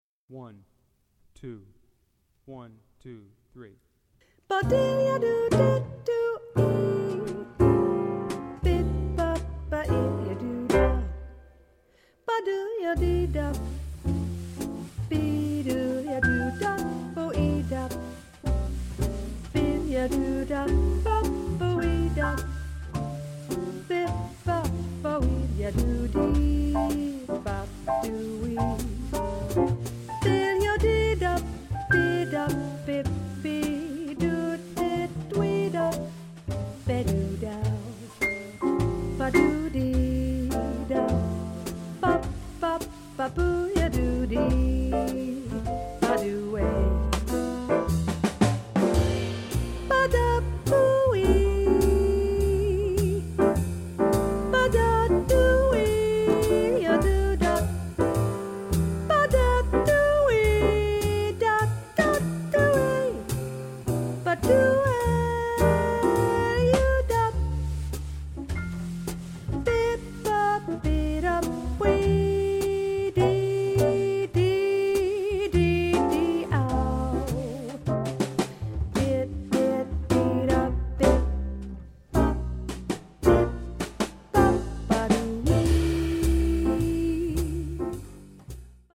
Voicing: Vocal